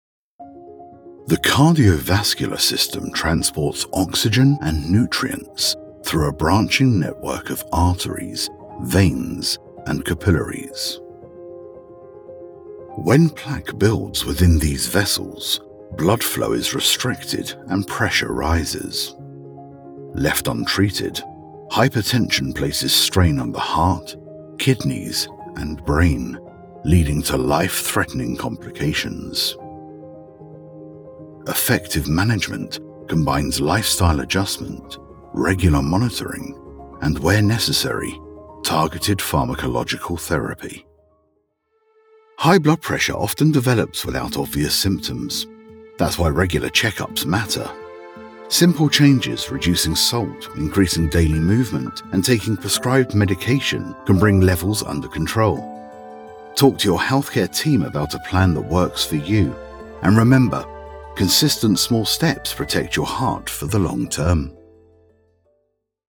british rp | natural
Medical_Narration_Demo.mp3